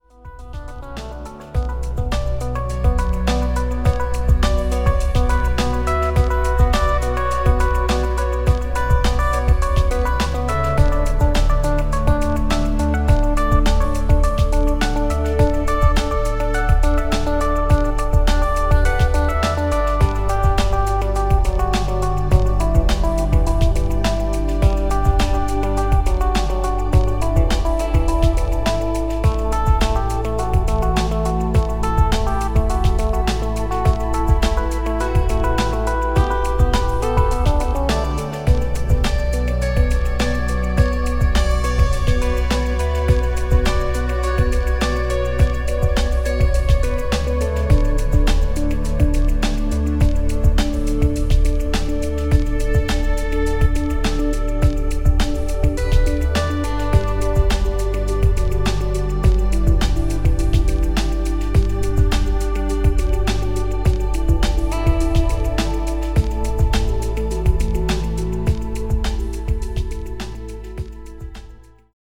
… and treated with the Cubase single band enhancer.
In this example the stereo widening is pretty impressive but the low end mix has been threatened and loses is clarity and focus.